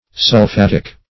Search Result for " sulphatic" : The Collaborative International Dictionary of English v.0.48: Sulphatic \Sul*phat"ic\, a. (Chem.)